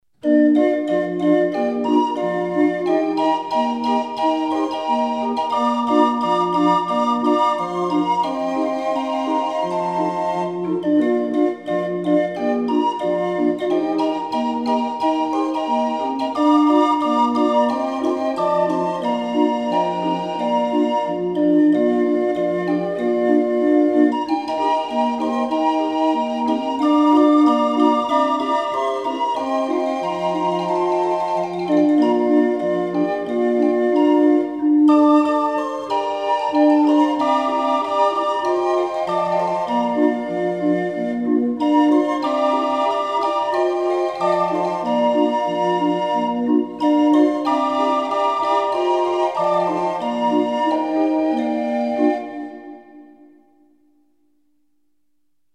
20 street organ